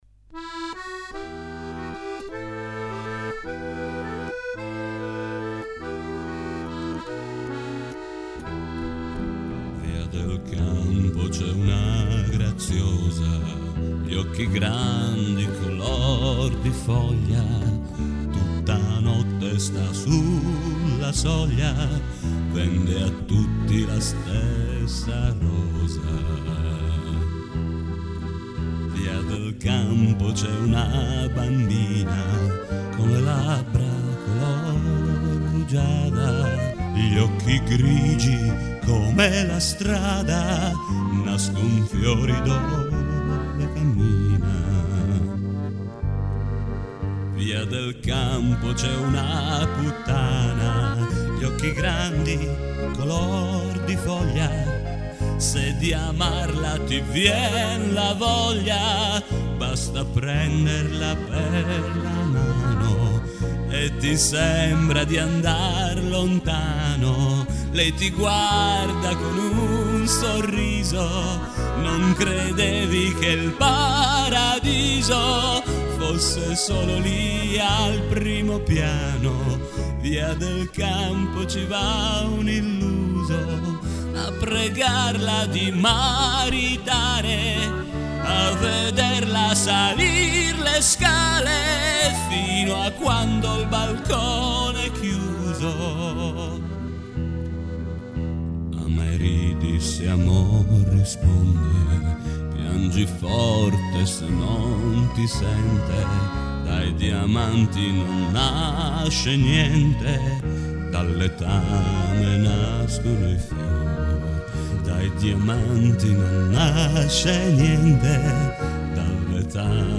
bandoneon
guitar
violin
the deep singing of an italian poet recently